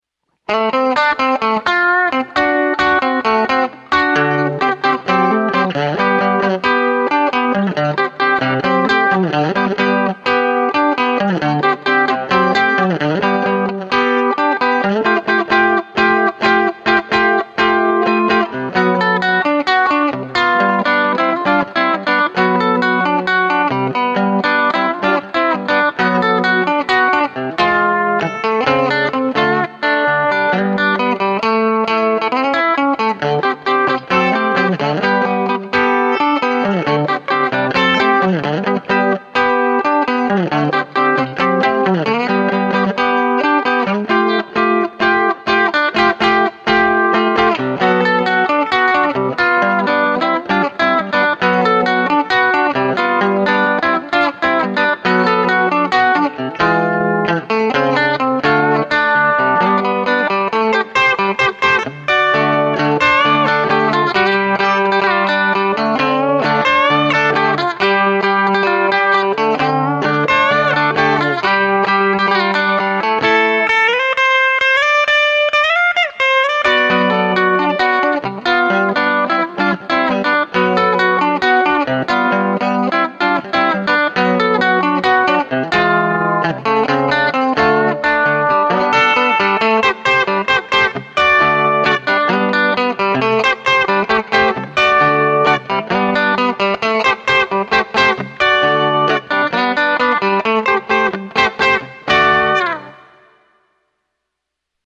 These are all single-take, no overdubbing